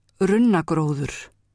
framburður